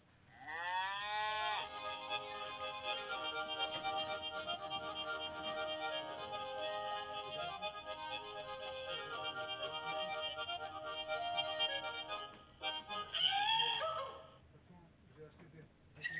Décapsuleur musicale suisse, jouant un air de yodle !
Lorsque vous ouvrez votre bouteille, le décapsuleur se met à chanter un joyeux air de Yodle.
Son joué par le décapsuleur au moment où vous ouvrez votre bouteille
decapsuleur-musical_yodel-4.1.mp3